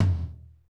TOM R B L0RR.wav